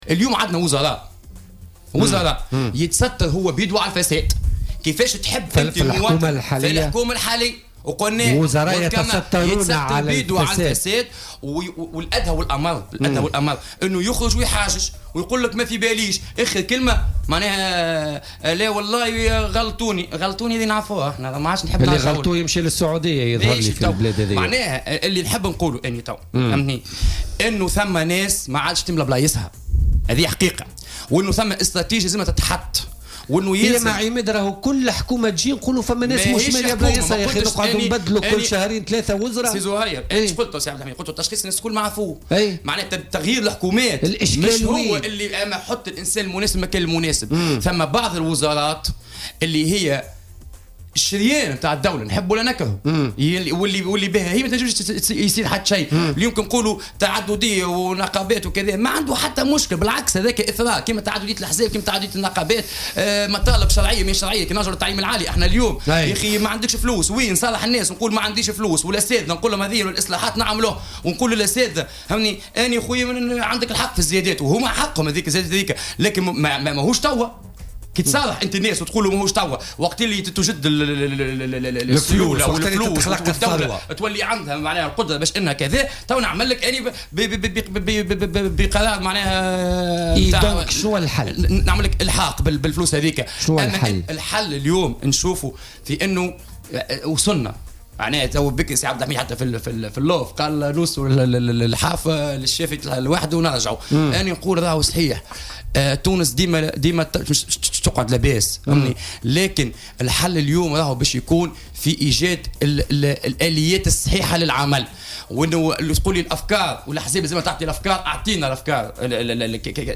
قال النائب بمجلس نواب الشعب عماد أولاد جبريل خلال استضافته اليوم الخميس في برنامج "بوليتيكا"، إن وزراء في الحكومة الحالية يتسترون على الفساد، ويرفضون فتح ملفات فساد خوفا على مناصبهم.